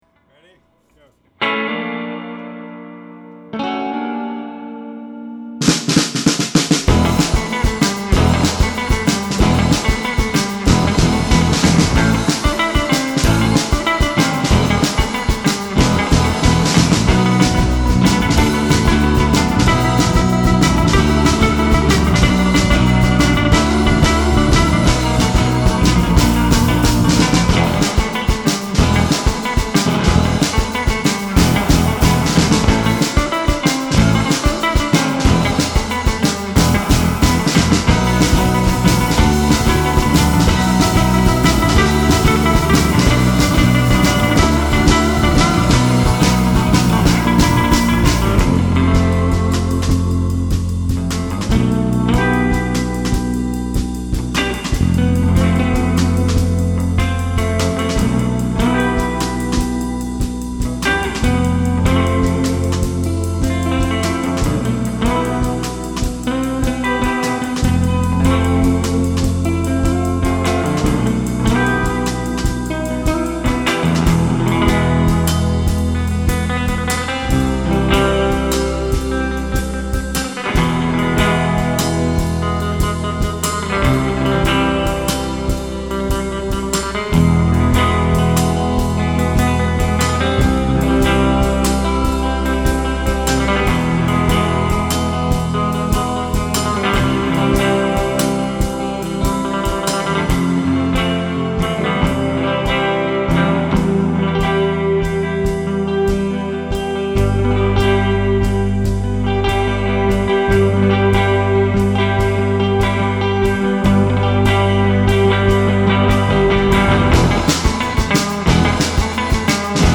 guitar.
drums.
bass.